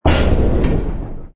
engine_br_freighter_kill.wav